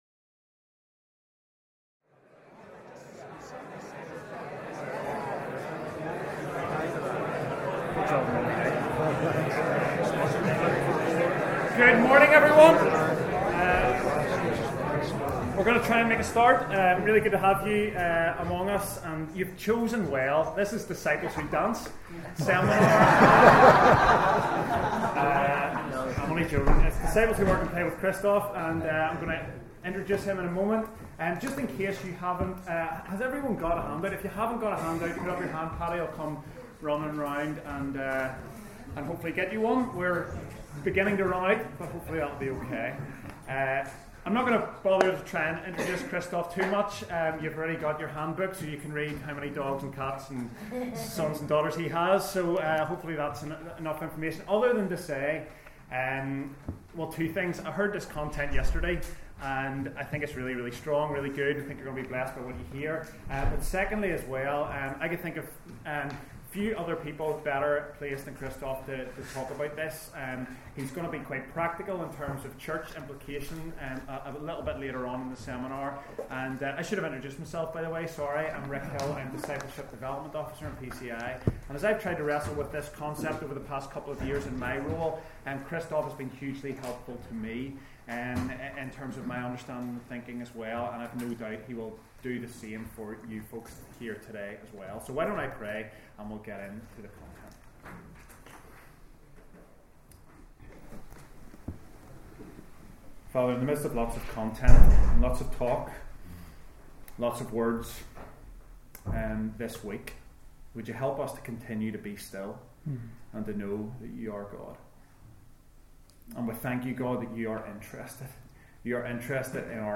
In this seminar we will be thinking about how our churches can become discipling communities that equip people for the whole of their real lives…not just Sundays, Monday to Saturday too…not just church, but all the places where they work and play.